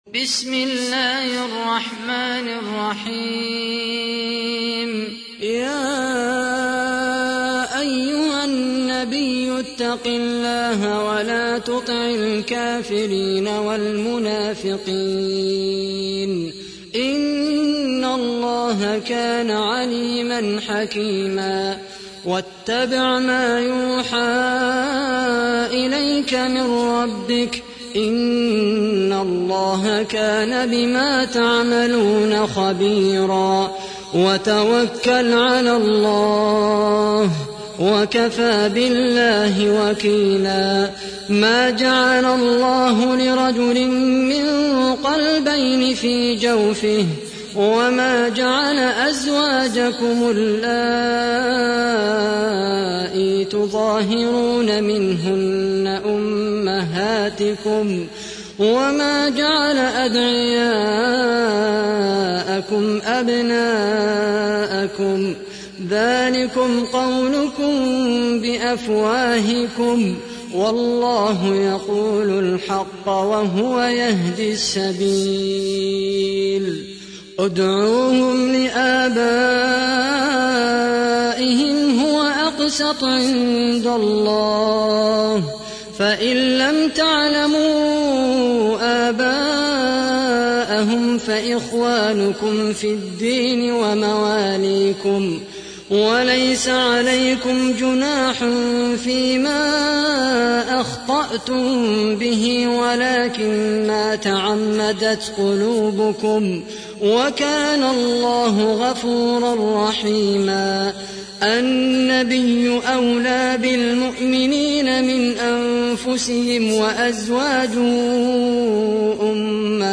تحميل : 33. سورة الأحزاب / القارئ خالد القحطاني / القرآن الكريم / موقع يا حسين